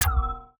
UIClick_Menu Wooble Metalic Resonate 03.wav